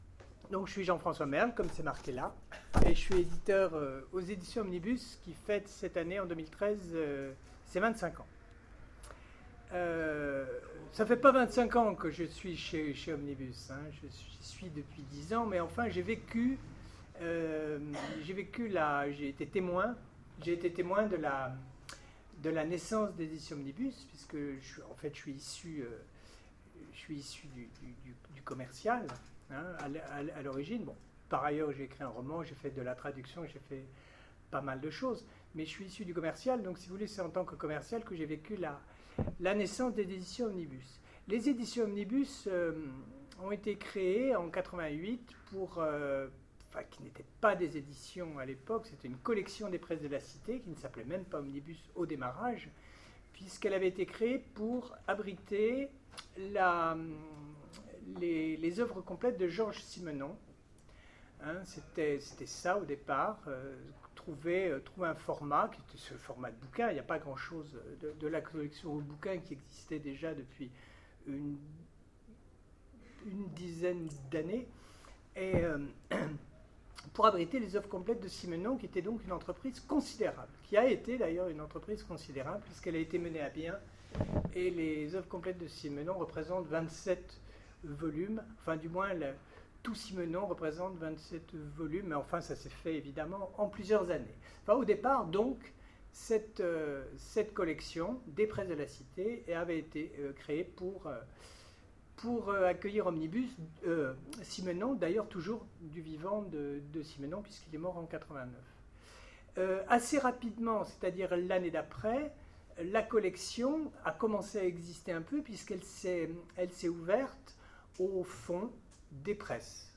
Sèvres 2013 : Conférence Les 25 ans d'Omnibus